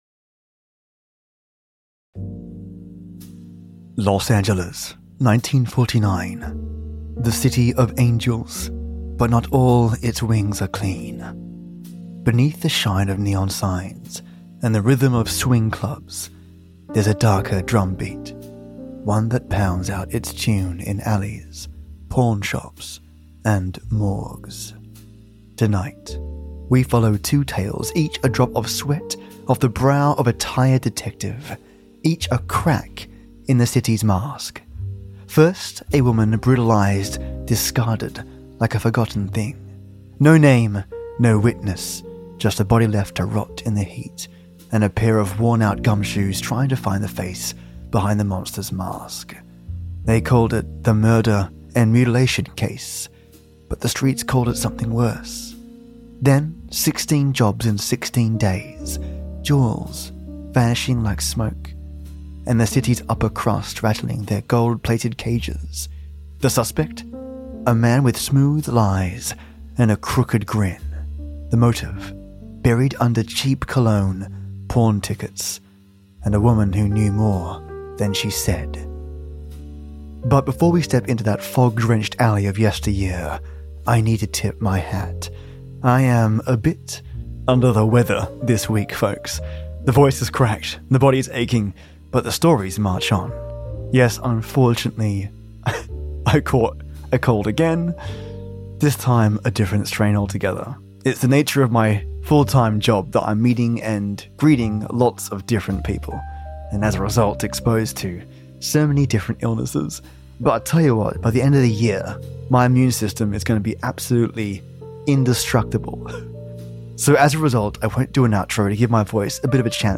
DRAGNET (Repaired and Remastered) Vintage Radio